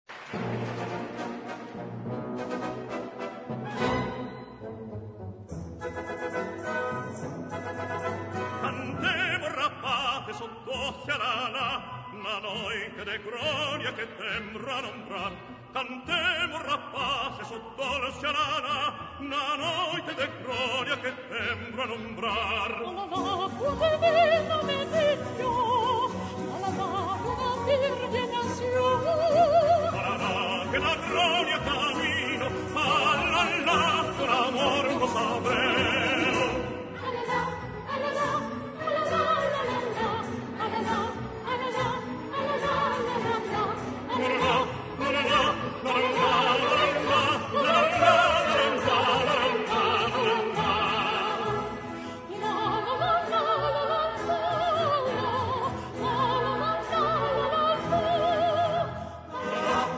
music: Traditional
key: E-major